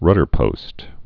(rŭdər-pōst)